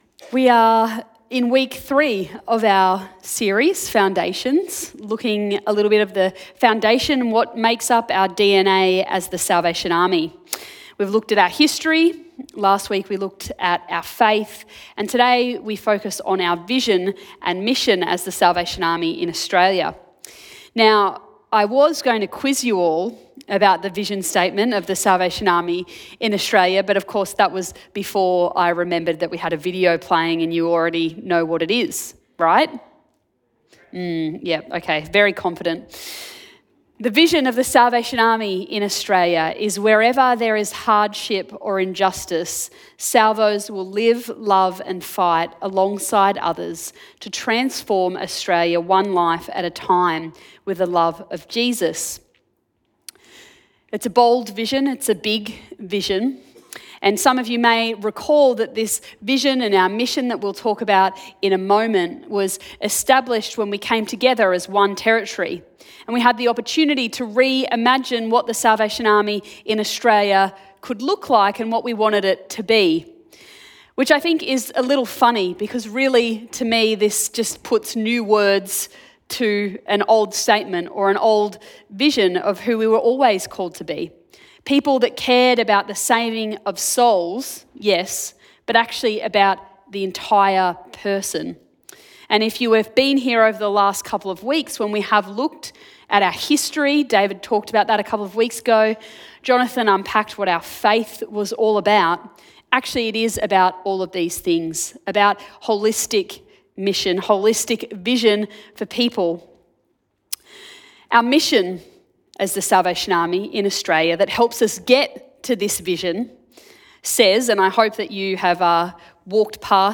Sermon Podcasts Foundations